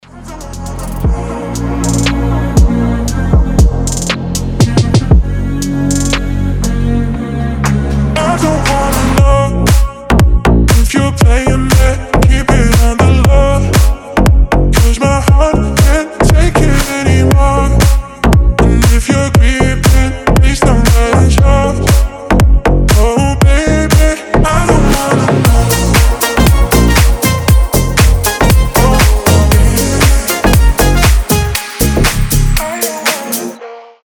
атмосферные
EDM
басы
ремиксы
slap house